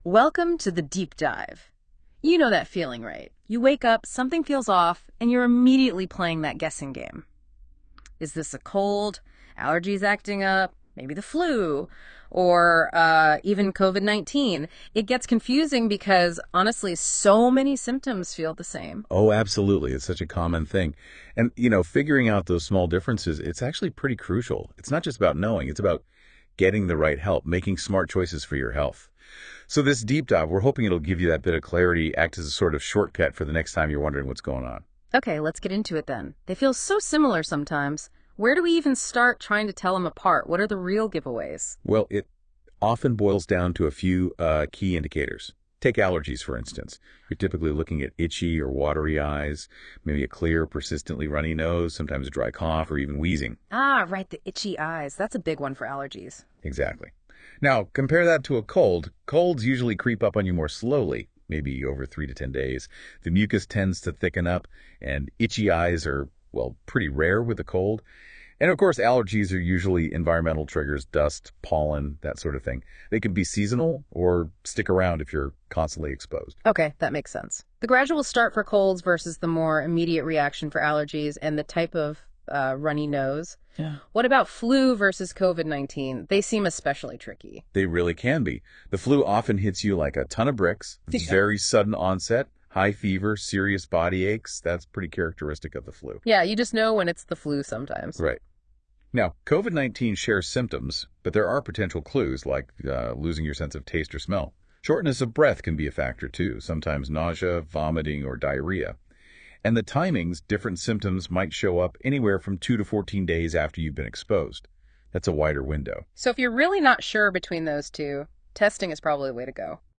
Check out our AI-generated audio summary: [audio